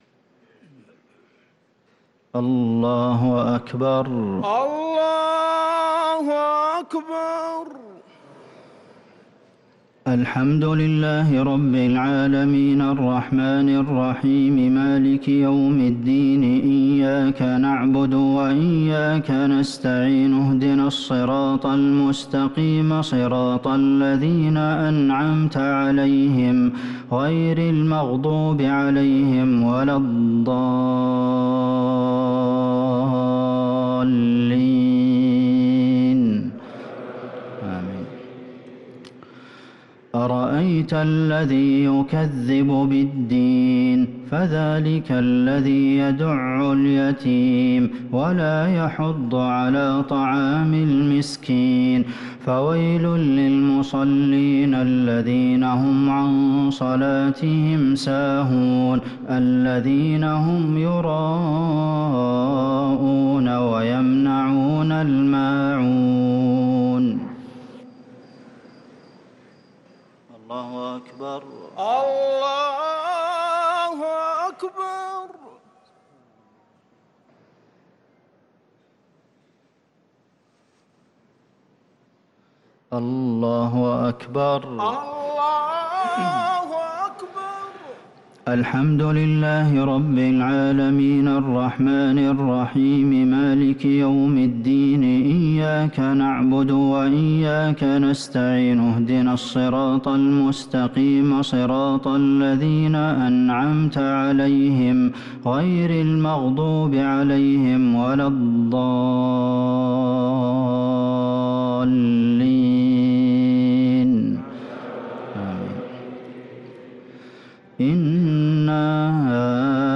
الشفع و الوتر ليلة 23 رمضان 1444هـ | Witr 23 st night Ramadan 1444H > تراويح الحرم النبوي عام 1444 🕌 > التراويح - تلاوات الحرمين